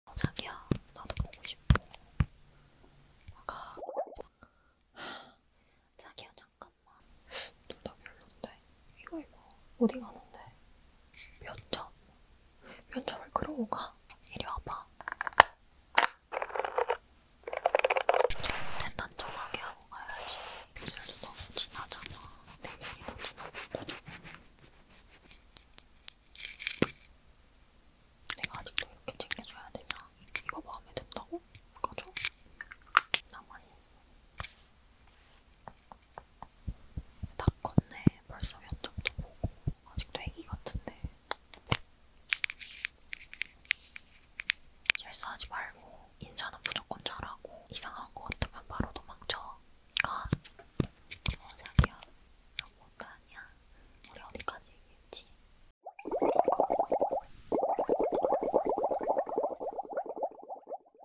까칠까칠한 친언니 Asmr😡 사실 오빠밖에 Sound Effects Free Download